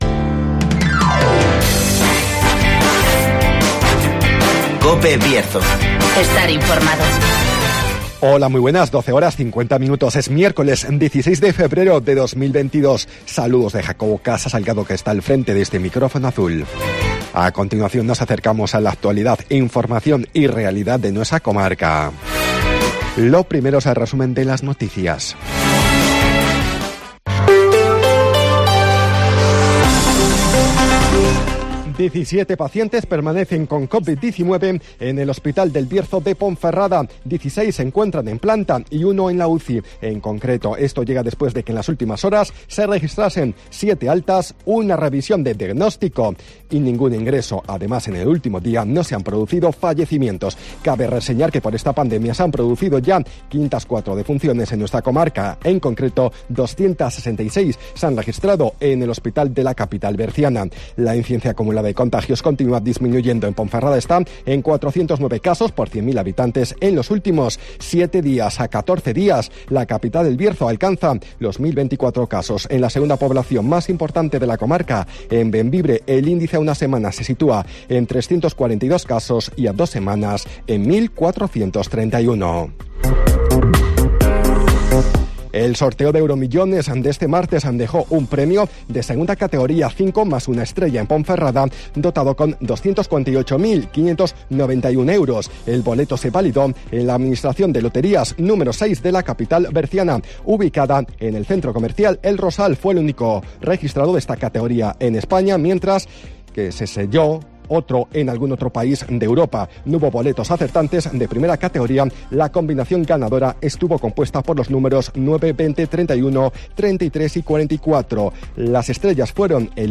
Resumen de las noticias, El Tiempo y Agenda